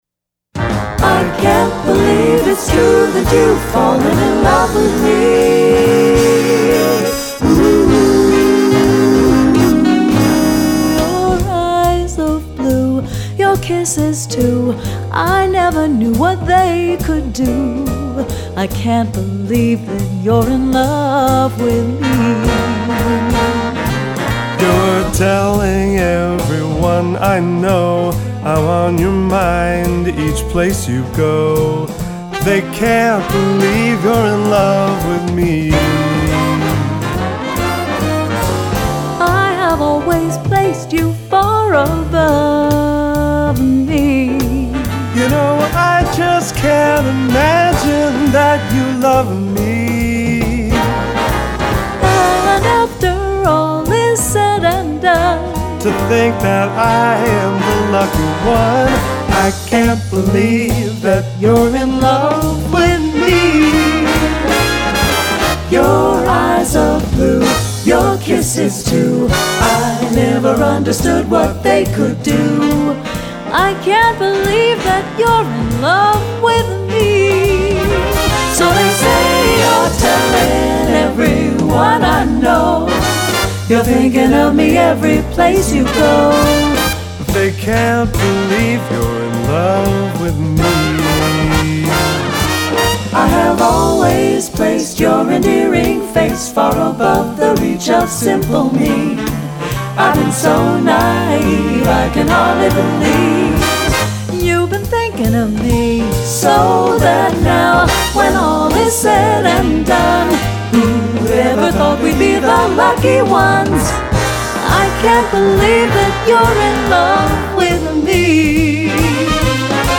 Voicing: SATB w/BB